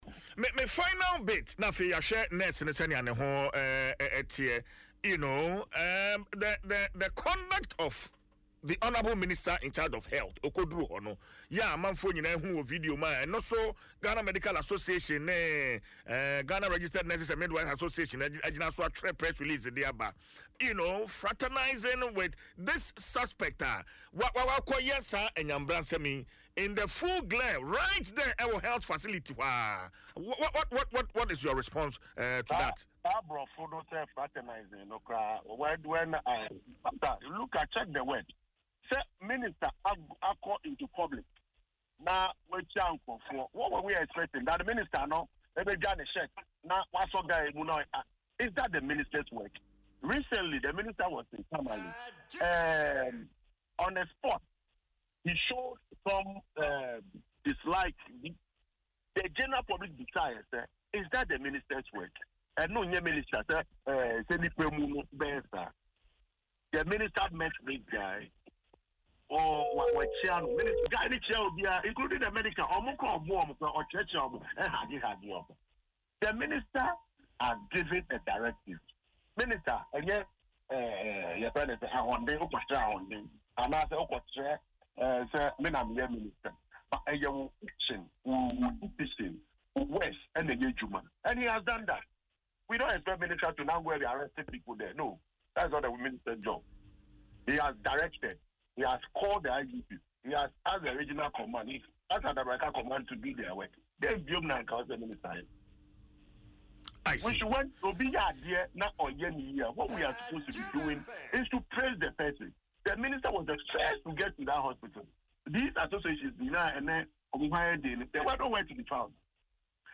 But in an interview on Adom FM’s Dwaso Nsem